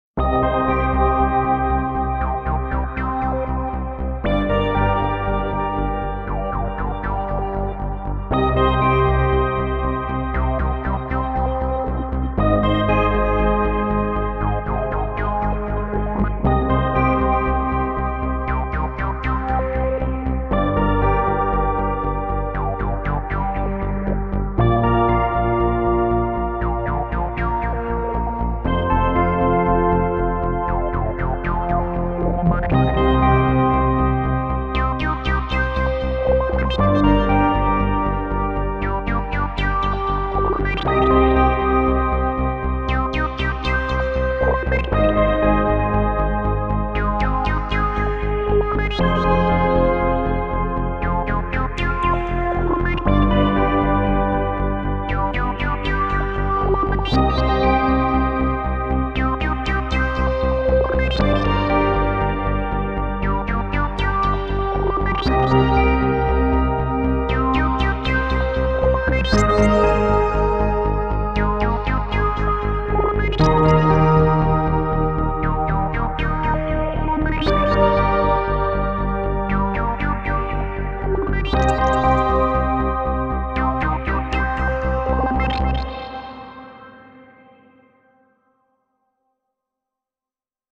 Genres: Background Music
Tempo: 118 bpm